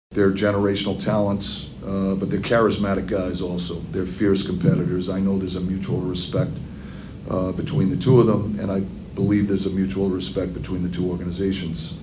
Everyone was talking about Sidney Crosby and Alex Ovechkin after the game, and each had a goal as they renewed their historic rivalry.  Coach Mike Sullivan enjoyed the show.